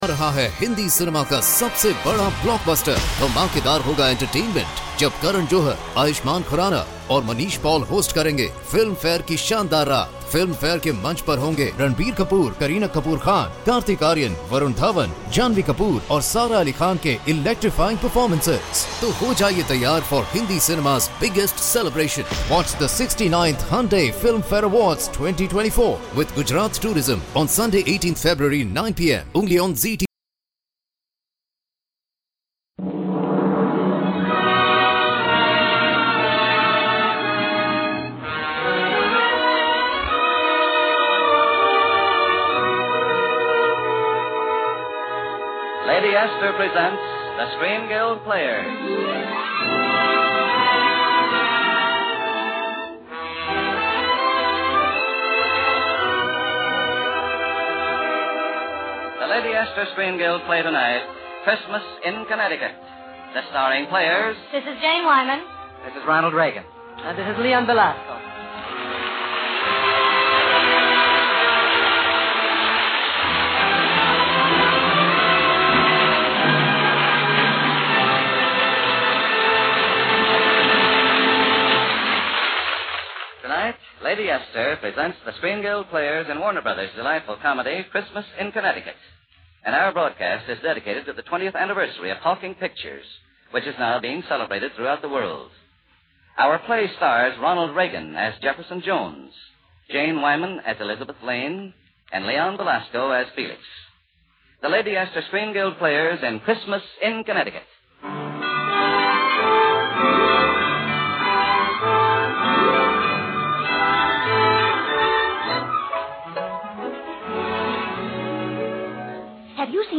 OTR Radio Christmas Shows Comedy - Drama - Variety.